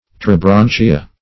Pterobranchia \Pter`o*bran"chi*a\, n. pl.